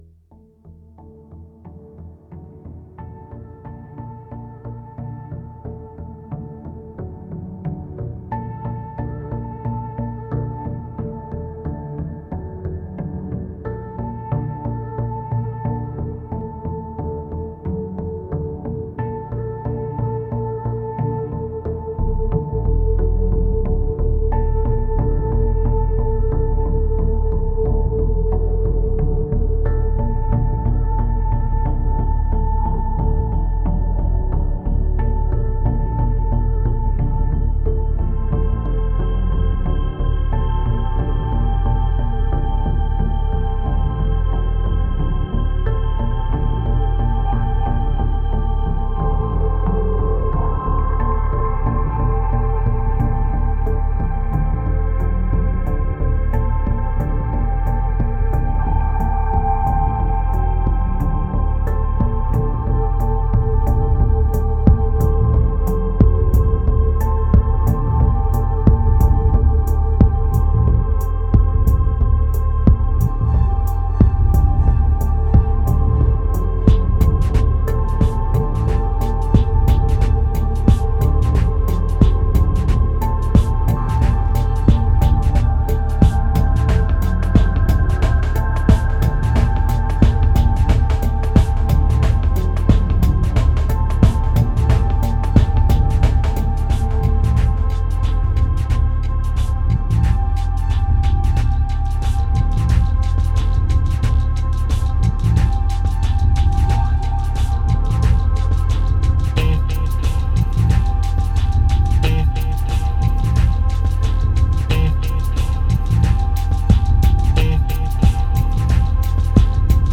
2513📈 - 5%🤔 - 90BPM🔊 - 2011-01-28📅 - -214🌟